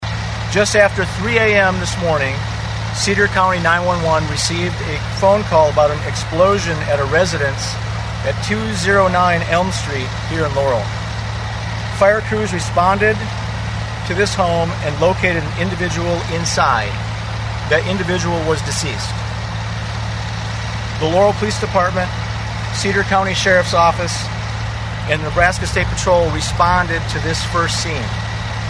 Speaking during a Thursday news conference, Nebraska State Patrol Colonel John Bolduc explained what they found.